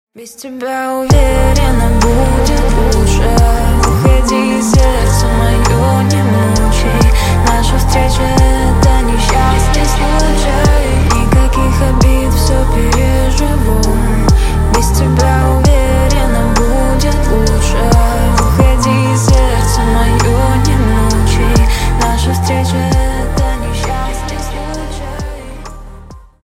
Грустные Рингтоны
Поп Рингтоны